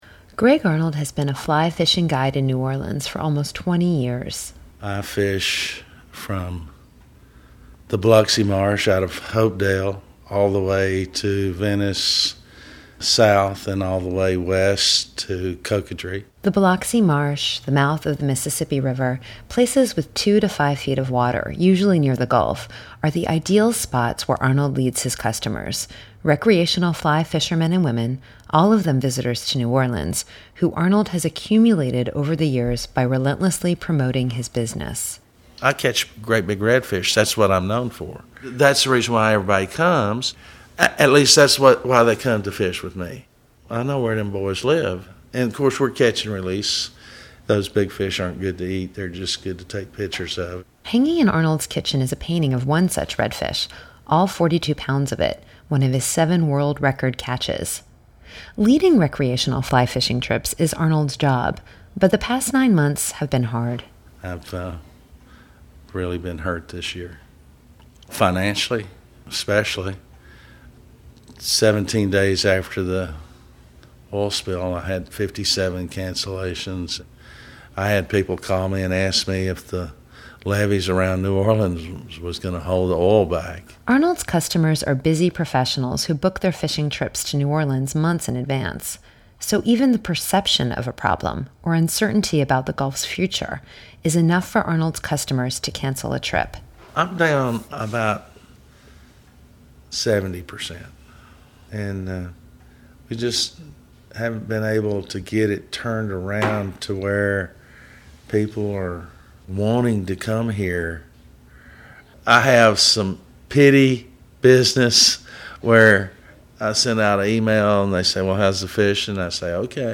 a New Orleans charter boat fisherman, was interviewed by WWNO Radio in New Orleans about his personal experience with the GCCF.